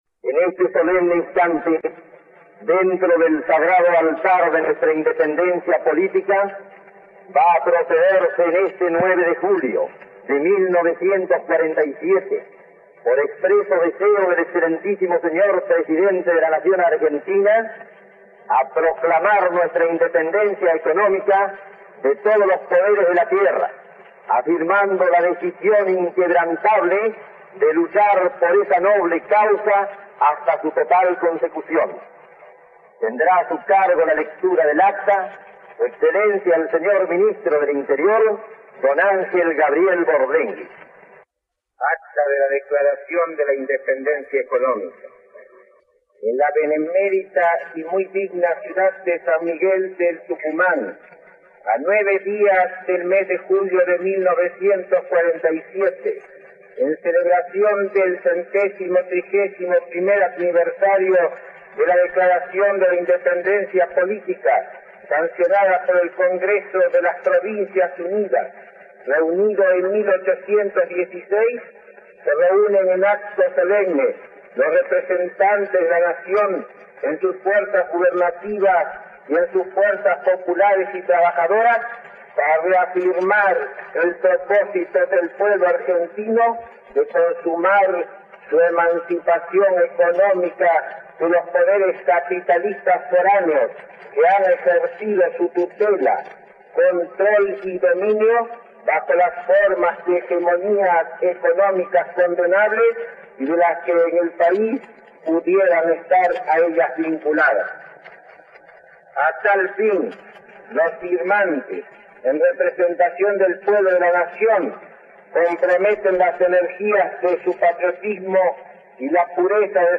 San Miguel de Tucumán, 9 de Julio de 1947, el Ministro del Interior, Ángel Borlenghi lee la declaración de la independencia económica.mp3